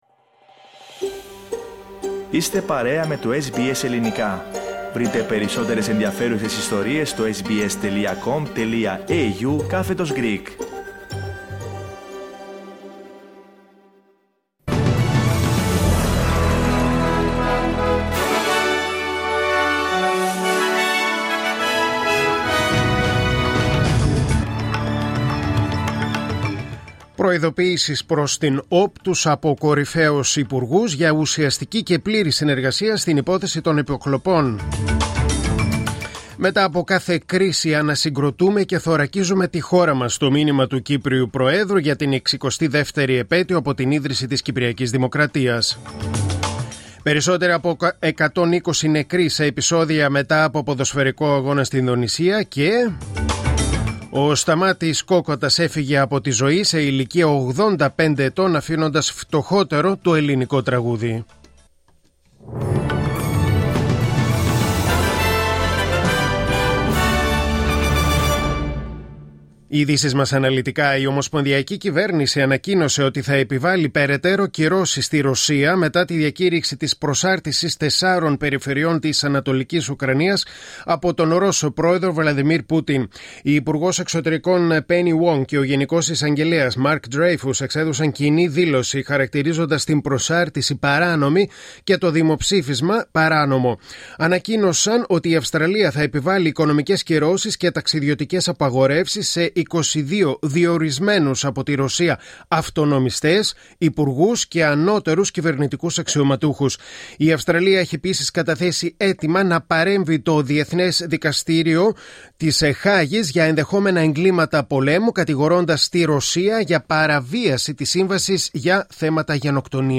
Δελτίο Ειδήσεων: Κυριακή 2.10.2022